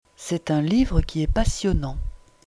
Voilà, il y a quelques années j’ai enregistré de courtes phrases, en voici une par exemple :
D’où il ressort que “Livre old” est un fichier MP3 de qualité passable et “Livre new” un fichier MP3 de qualité médiocre (niveau téléphone).